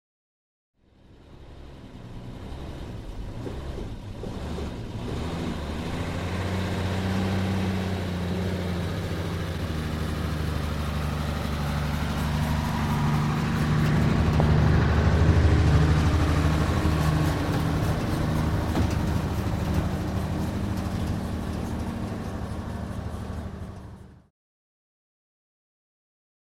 Шум передвижения фургона